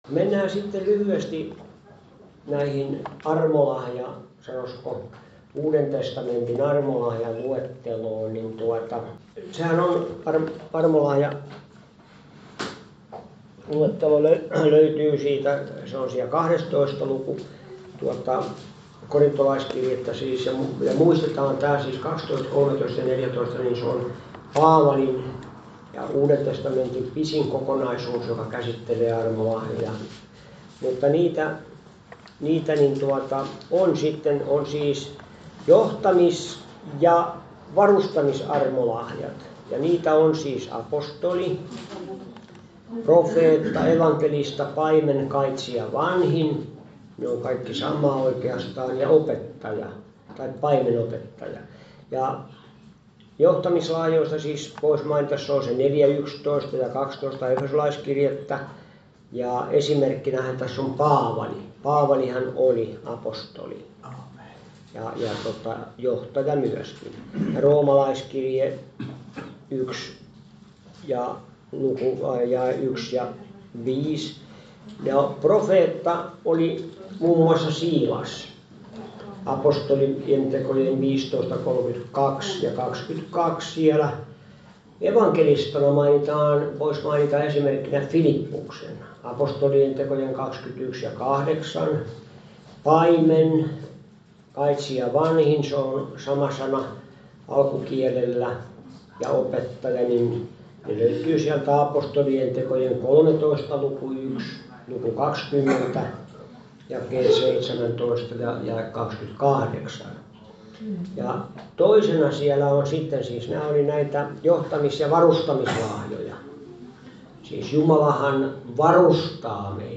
Vantaan Kotikirkko - Kuuntele puheita netissä
Service Type: Raamattutunti